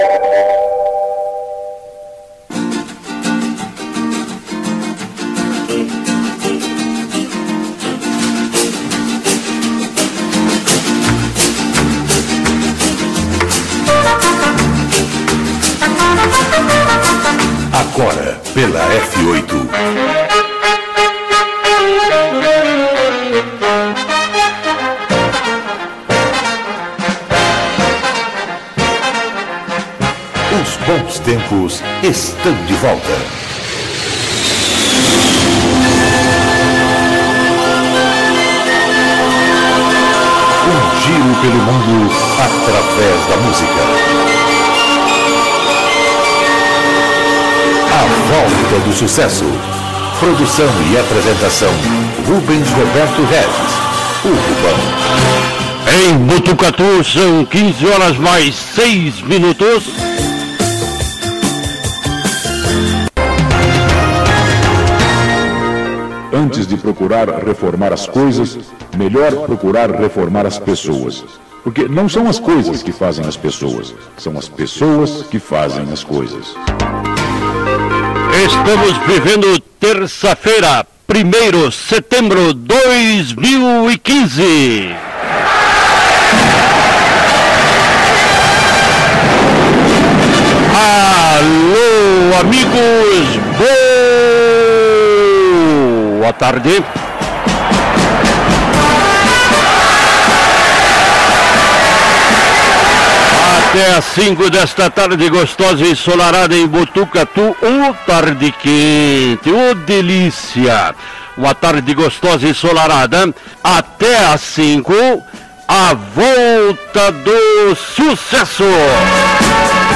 Durante todo do programa, num bate-papo descontraído e acompanhado de muito boa música, a nossa história foi relembrada deste seu início até os dias atuais, também pudemos interagir com com alguns ouvintes do programa pelo telefone, parabenizando nosso clube.